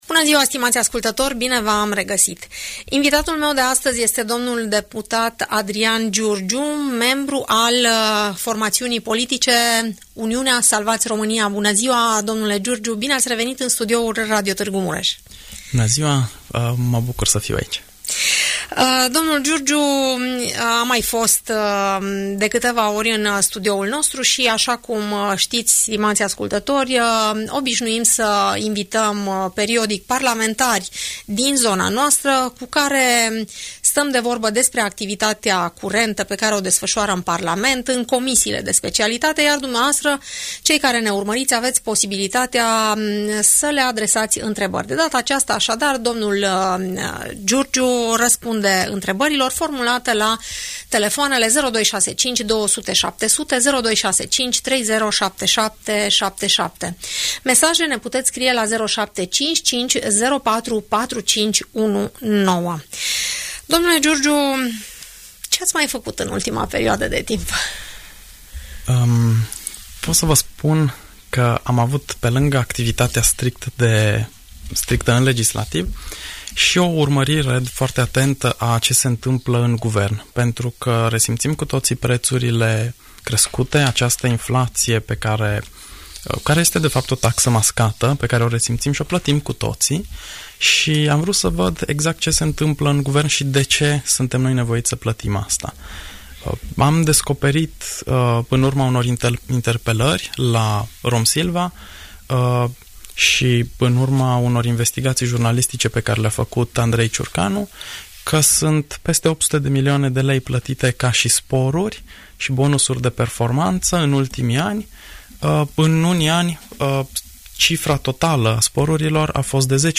Deputatul Adrian Giurgiu, din partea USR, în direct la Radio Tg Mureș